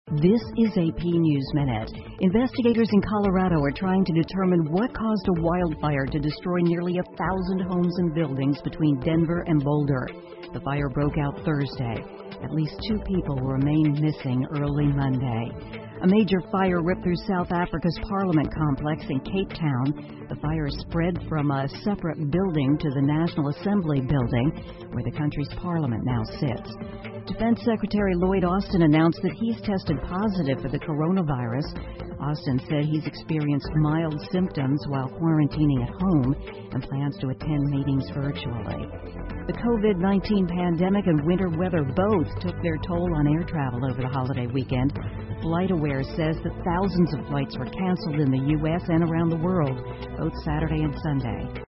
美联社新闻一分钟 AP 南非议会大厦发生火灾 听力文件下载—在线英语听力室